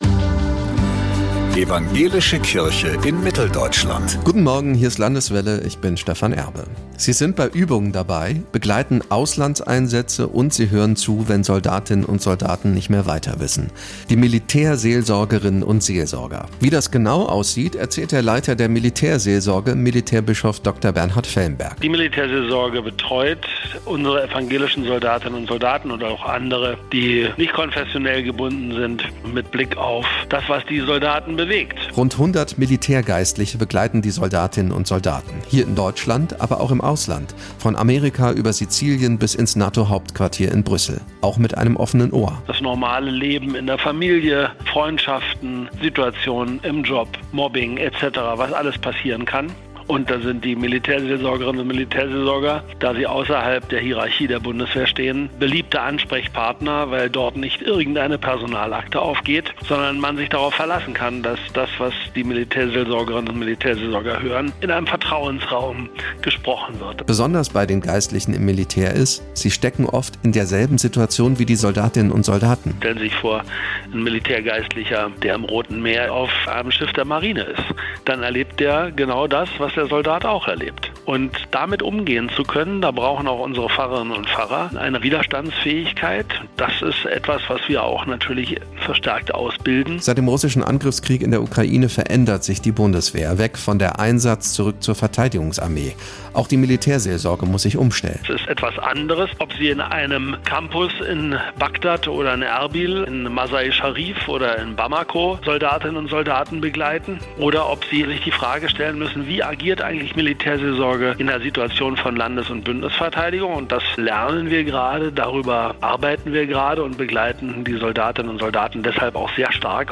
Mehr als 90 Prozent der Soldatinnen und Soldaten haben großes Vertrauen in die Militärseelsorge. Das ist das Ergebnis einer jüngst veröffentlichten Studie. Deshalb, sagt Bernhard Felmberg, Evangelischer Bischof für die Seelsorge in der Bundeswehr oder kurz: Militärbischof: